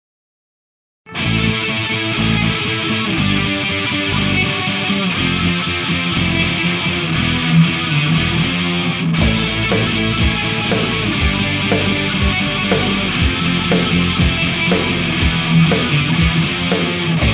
Distortion ds-1
Distortion-ds-1.mp3